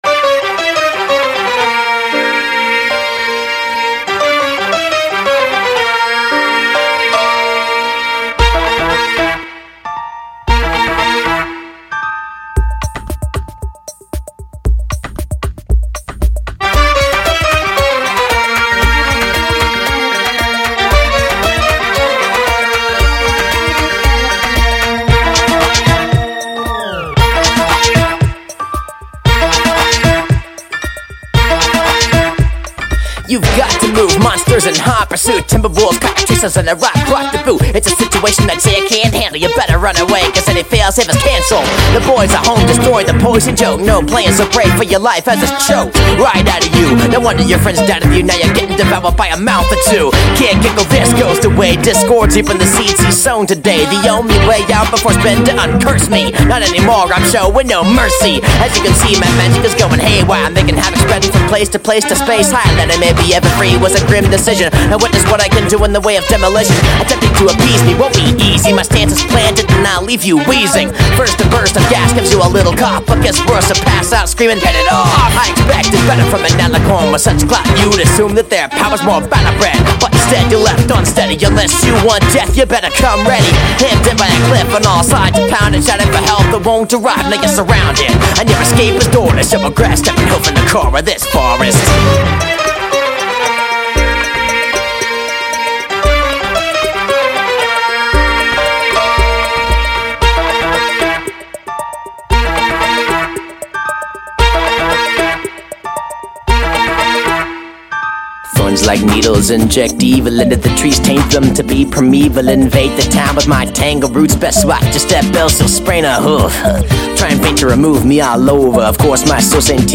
Just an amazing job, ferocious rapping, and a seriously talented cool guy!.